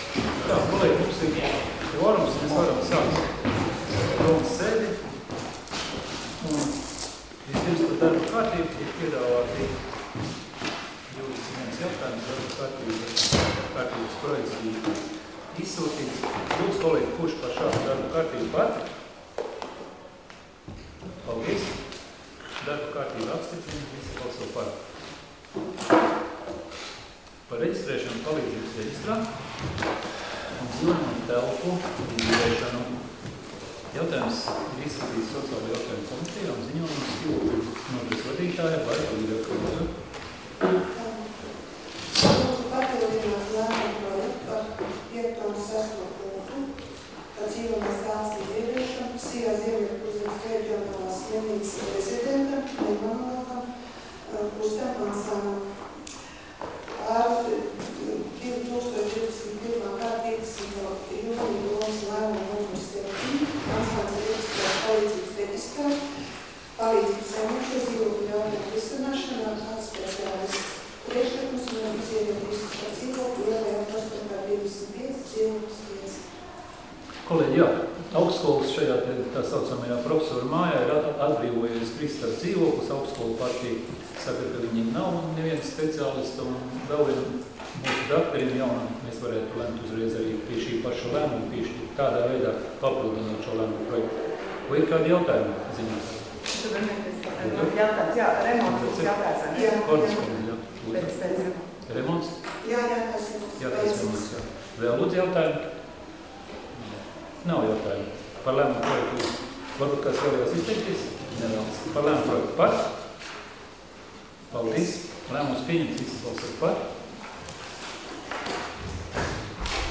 Domes sēdes 27.05.2021. audioieraksts